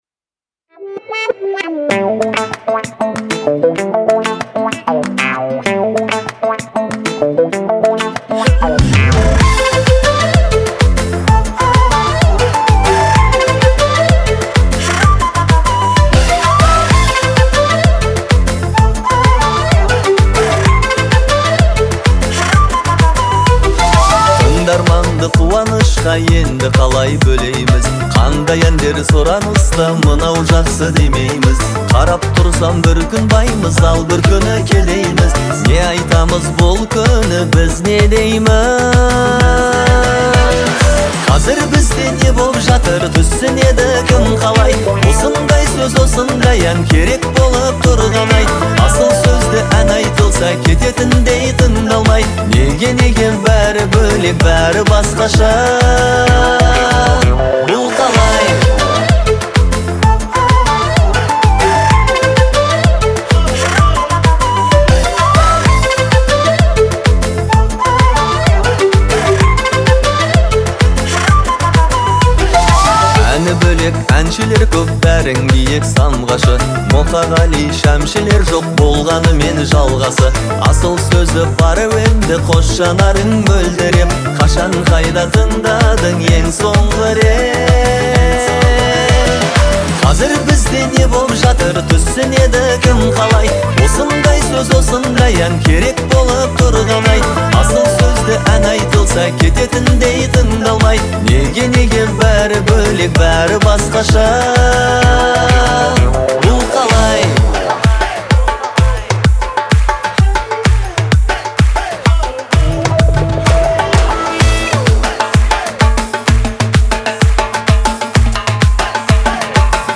глубокий вокал и выразительные мелодии